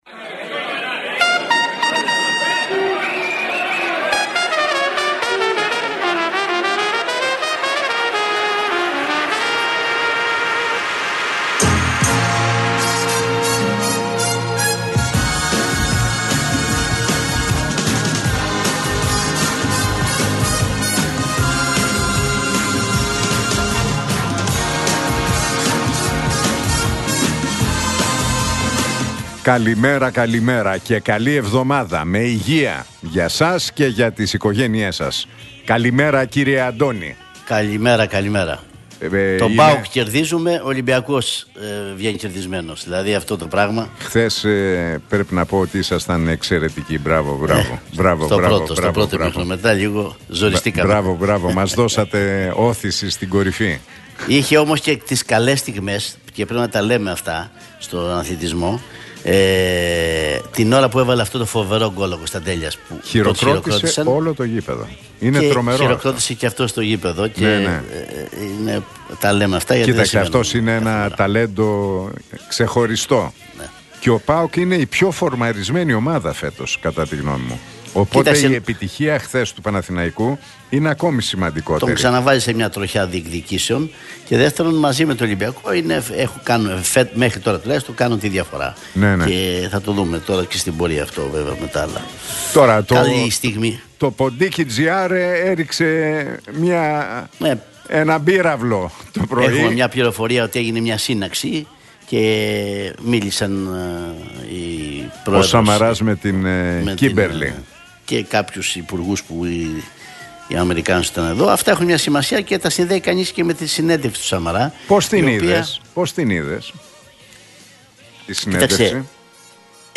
Ακούστε την εκπομπή του Νίκου Χατζηνικολάου στον ραδιοφωνικό σταθμό RealFm 97,8, την Δευτέρα 10 Νοεμβρίου 2025.